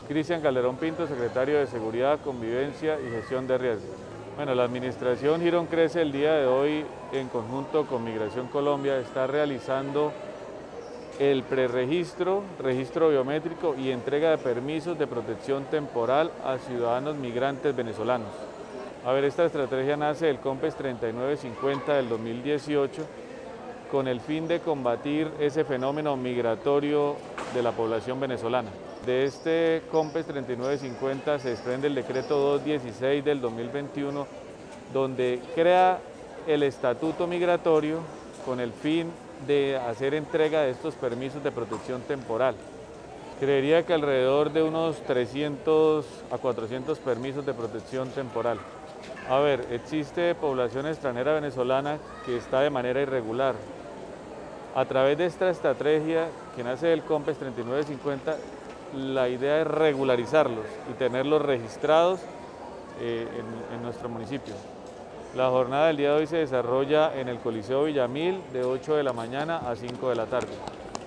Cristian Calderon. Secretario de Seguridad.mp3